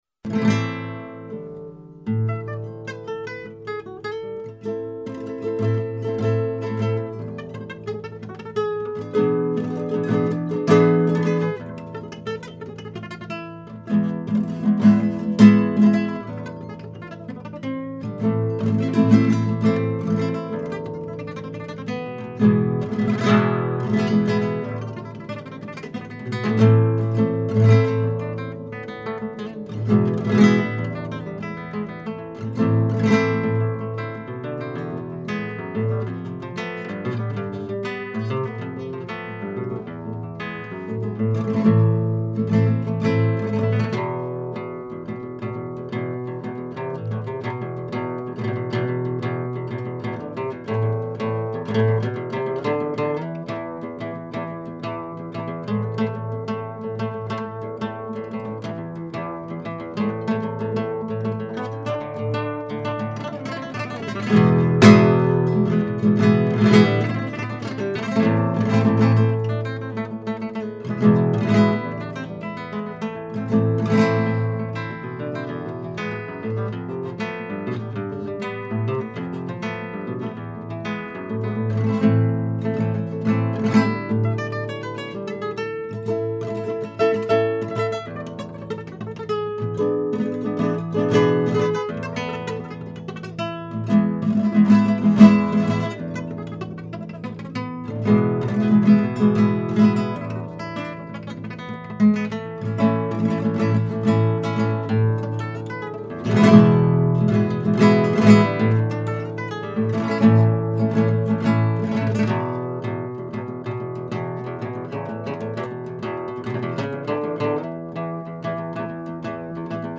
Фламенко үлгүсүндөгү Испаниянын салттуу музыкасы